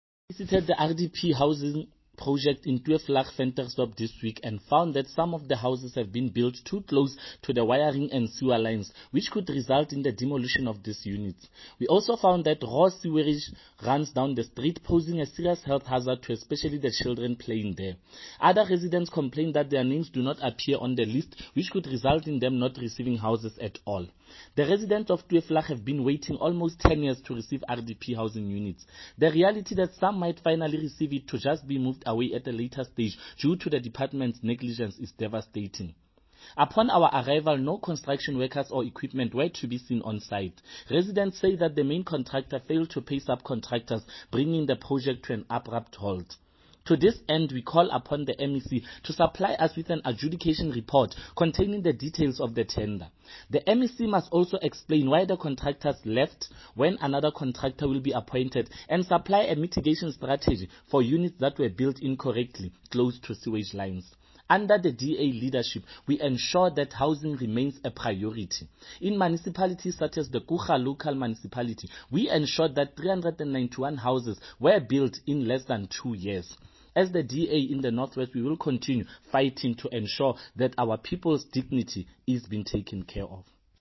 Note to Editors: Kindly find attached soundbites in
Setswana by DA North West Spokesperson on COGHSTA, Freddy Sonakile.